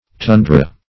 Tundra \Tun"dra\ (t[=oo]n"dr[.a]), n. [Russ.]